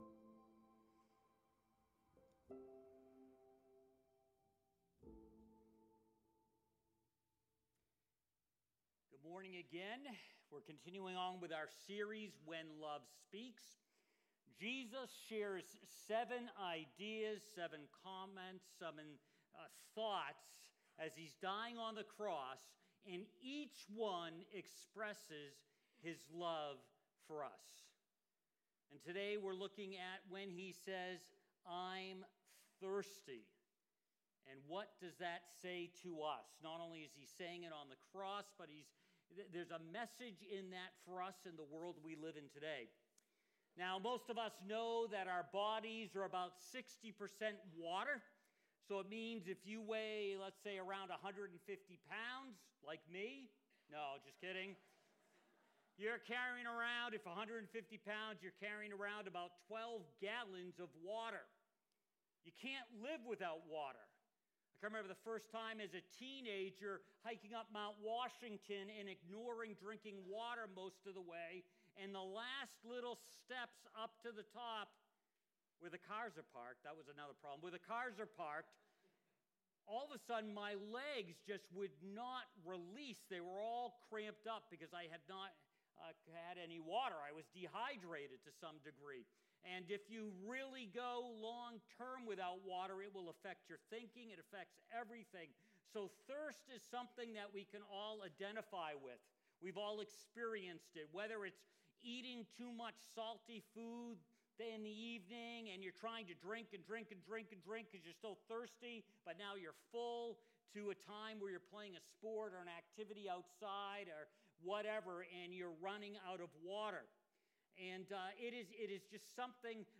Read John 19:28-29 and join us for our Easter Sunday Service, as we continue our series, When Love Speaks: Last Words from the Cross, with Part 5: Thirst.